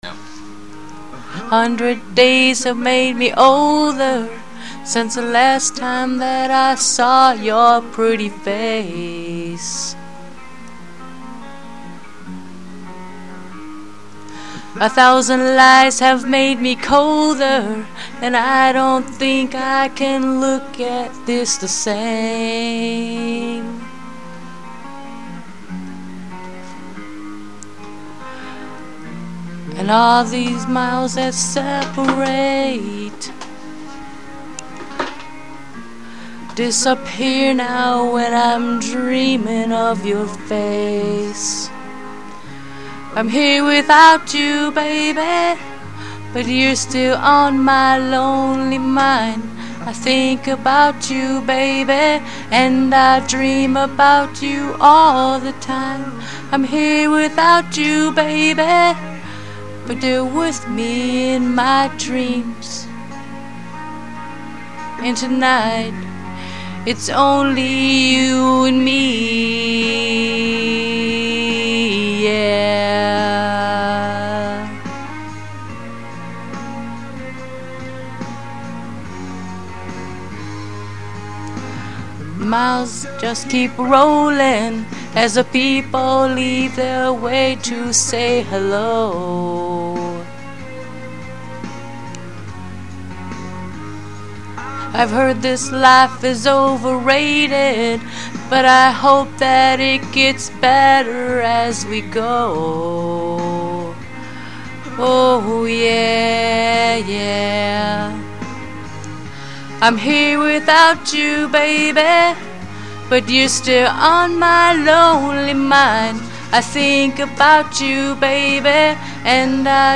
Hip-hop
Pop